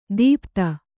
♪ dīpta